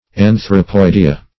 \An`thro*poid"e*a\